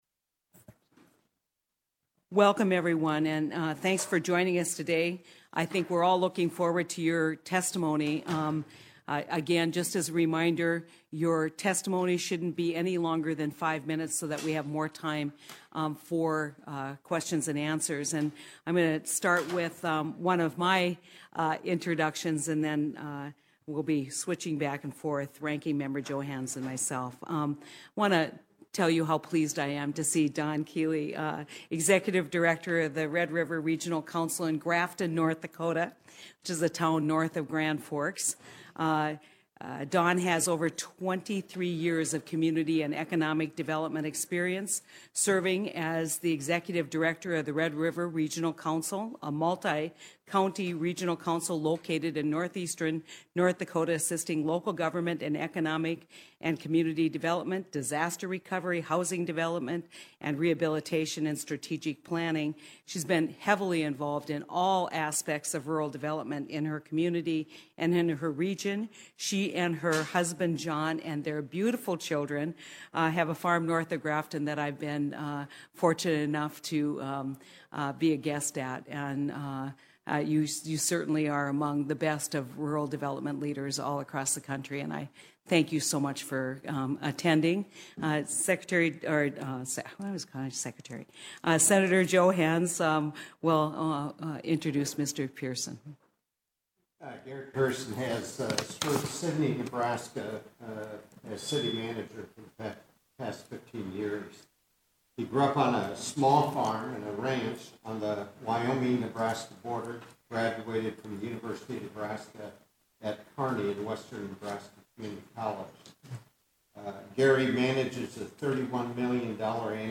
AUDIO: Senate Ag Subcommittee hearing.